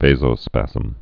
(vāzō-spăzəm)